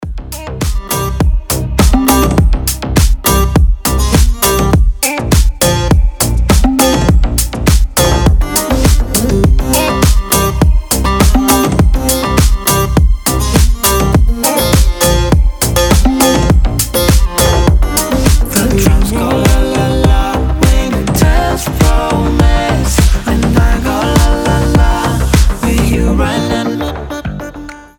• Качество: 320, Stereo
deep house
зажигательные
басы
восточные
electro house